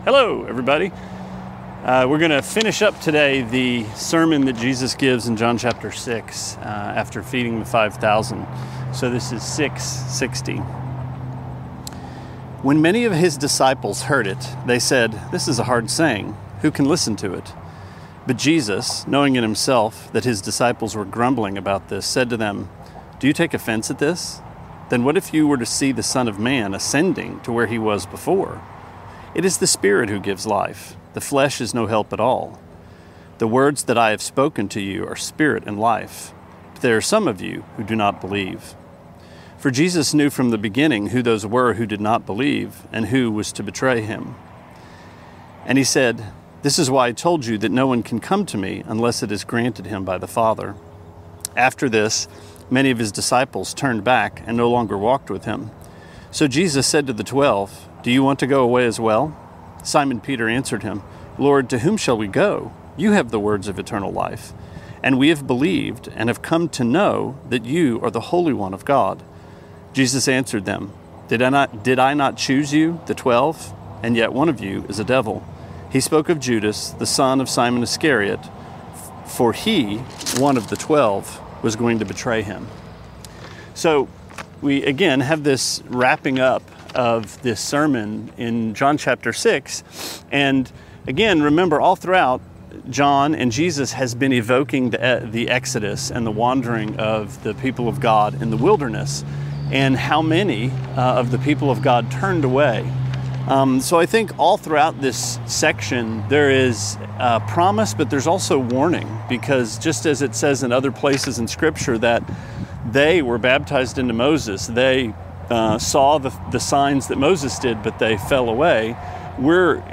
Sermonette 5/7: John 6:60-71: The Flesh Can’t Help